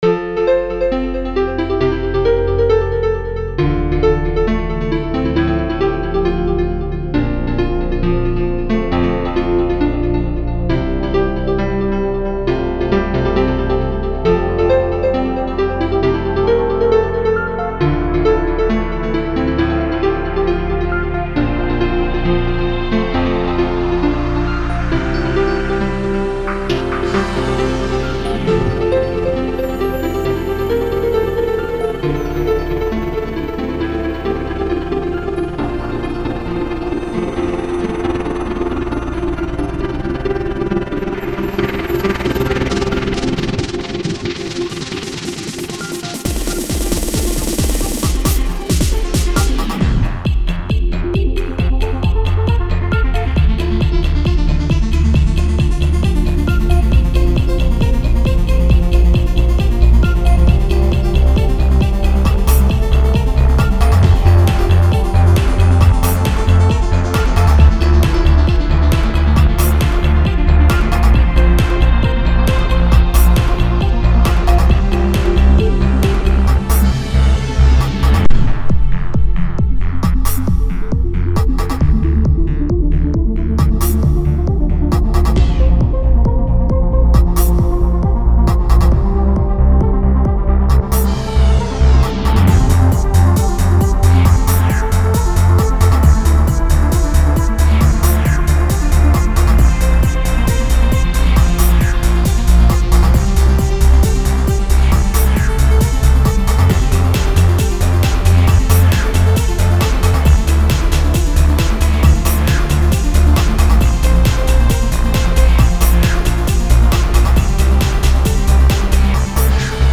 Electronic Music© 2008 License: Creative Commons